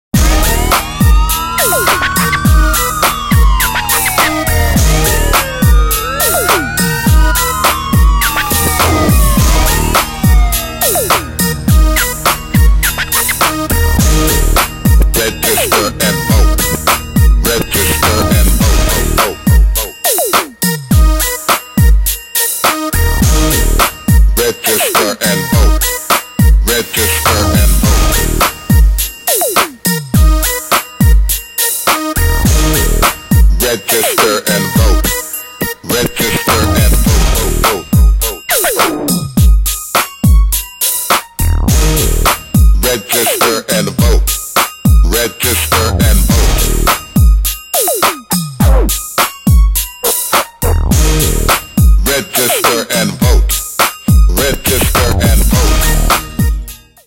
Radio advertisements